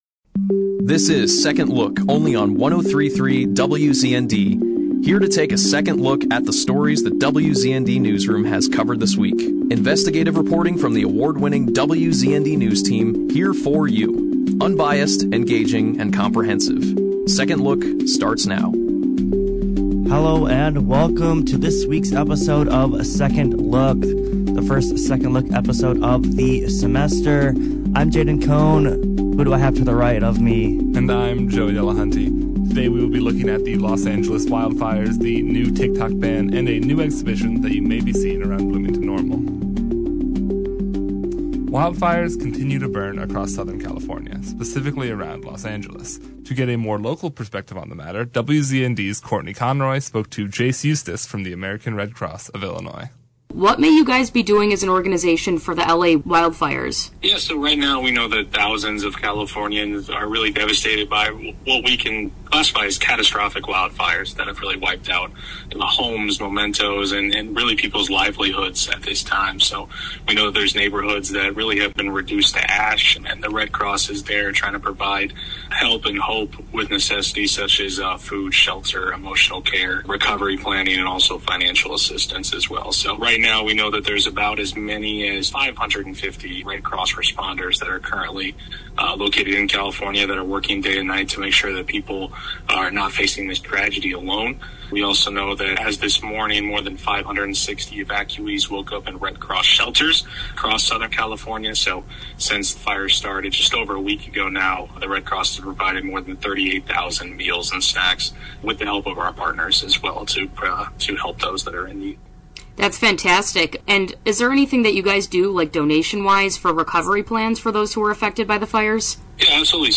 The show opened with an interview